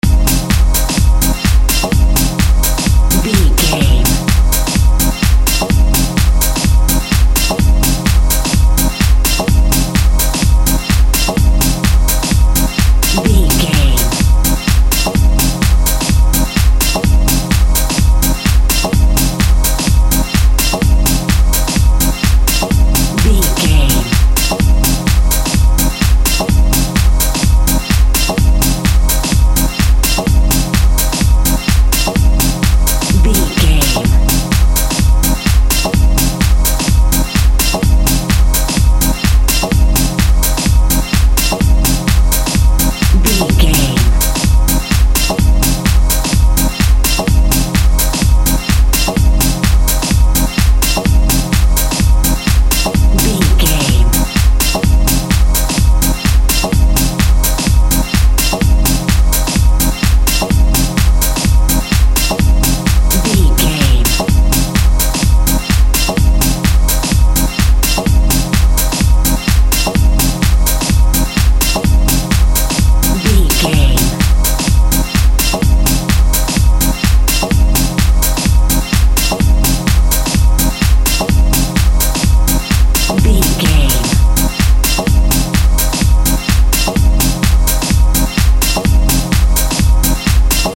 Progressive Electronics.
Aeolian/Minor
groovy
futuristic
energetic
driving
repetitive
drum machine
synthesiser
progressive house
synth lead
synth bass